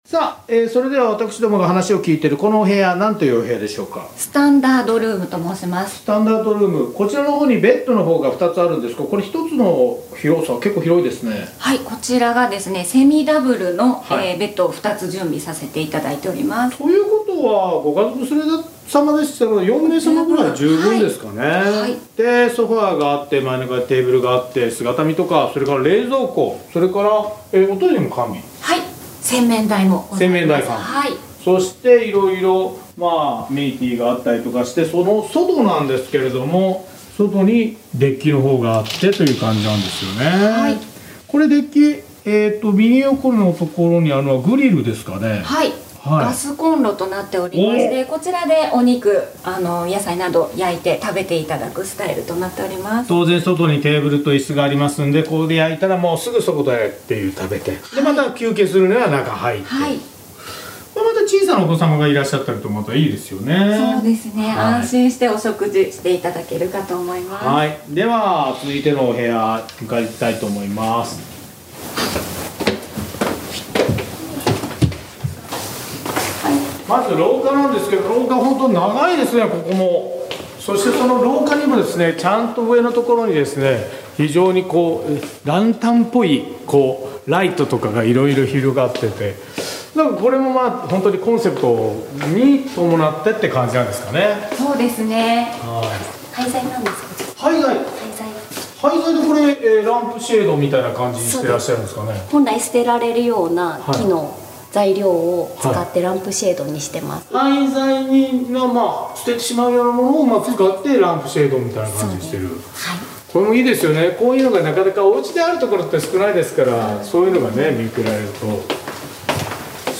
毎週土曜午前11時から生放送。